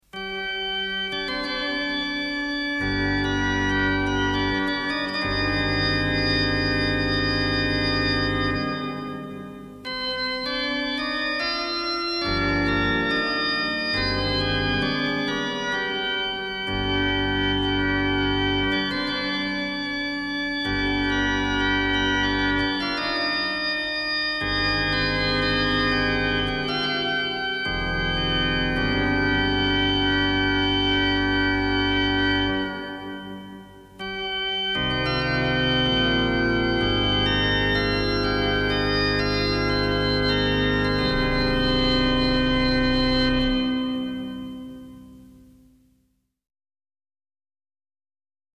1968 sad slow instr.